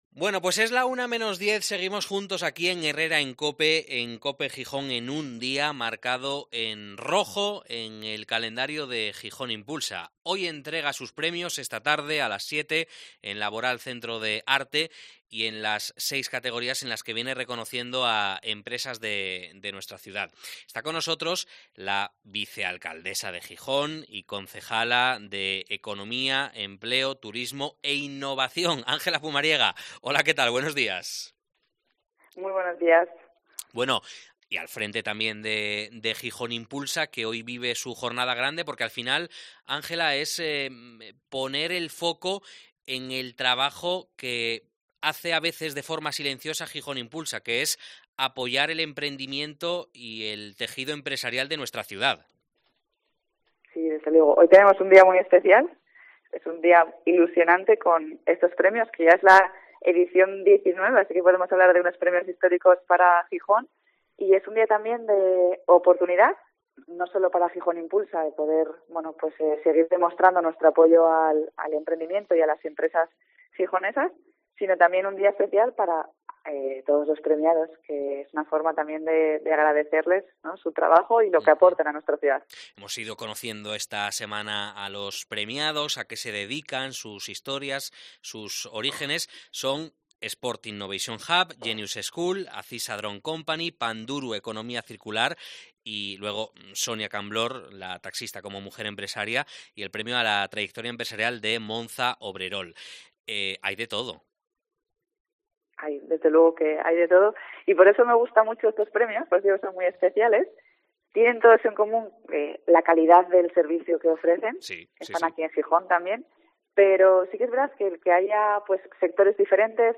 Entrevista a la concejala de Economía y Empleo de Gijón, Ángela Pumariega